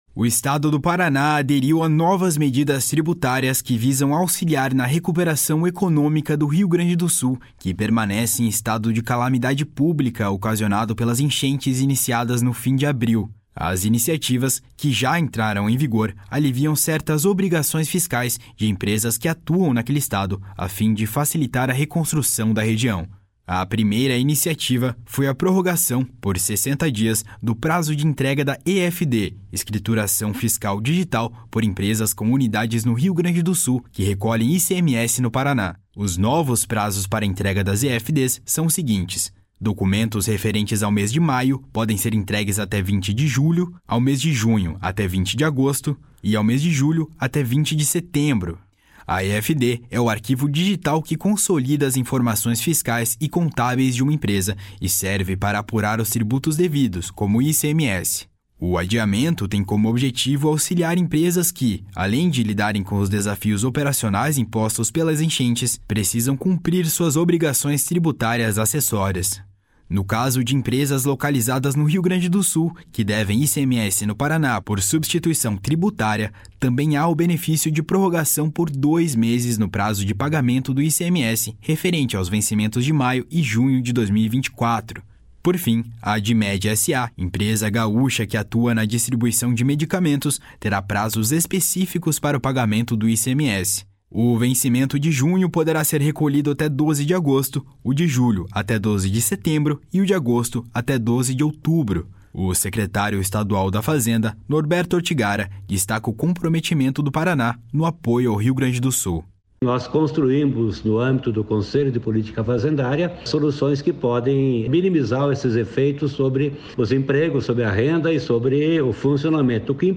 O secretário estadual da Fazenda, Norberto Ortigara, destaca o comprometimento do Paraná no apoio ao Rio Grande do Sul. // SONORA NORBERTO ORTIGARA //